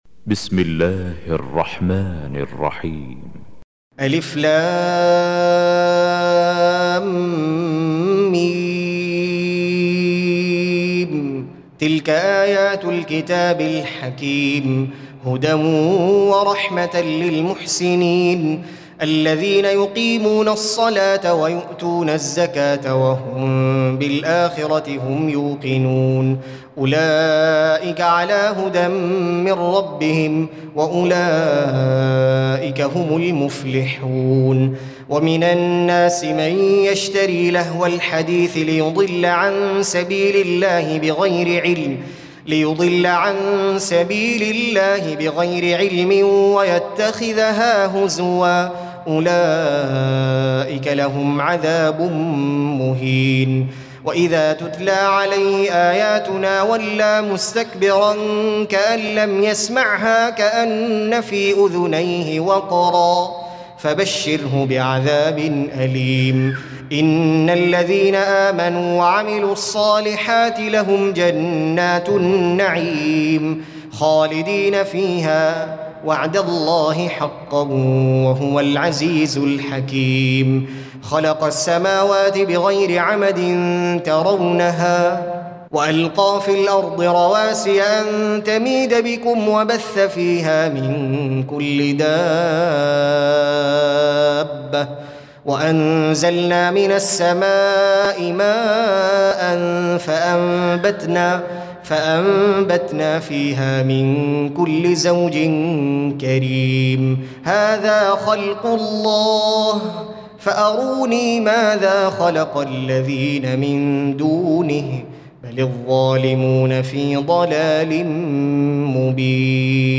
Reciting Murattalah Audio for 31. Surah Luqmân سورة لقمان N.B *Surah Includes Al-Basmalah